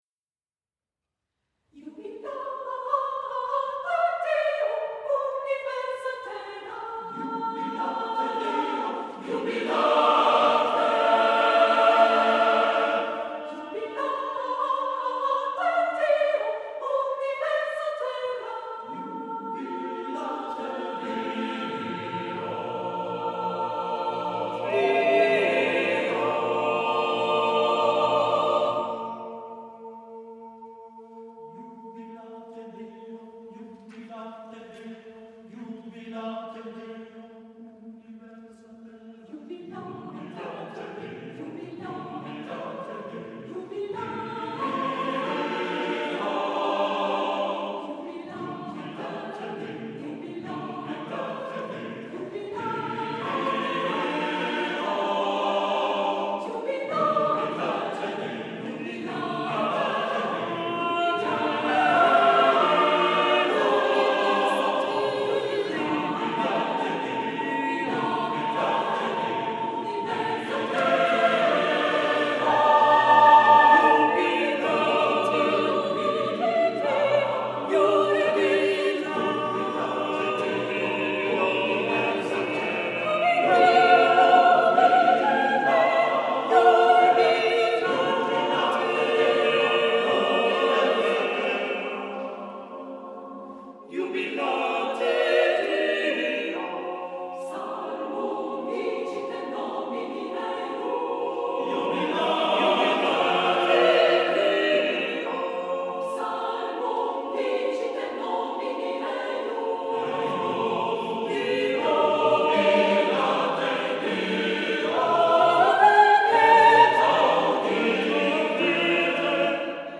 Voicing: SSATBB